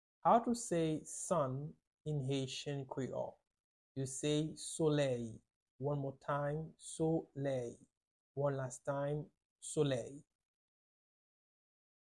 Listen to and watch “solèy” audio pronunciation in Haitian Creole by a native Haitian  in the video below:
18.How-to-say-Sun-in-Haitian-Creole-–-soley-with-Pronunciation.mp3